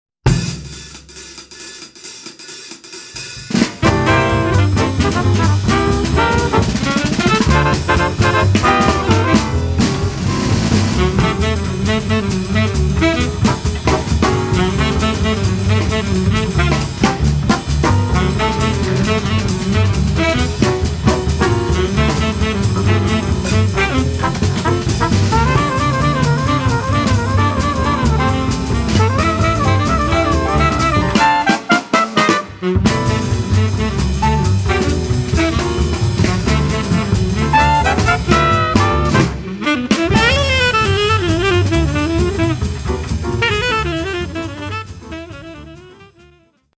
jumping new meets hopping old school fun...
tenor & baritone sax
alto sax
trumpet & flugellhorn
trombone
piano
bass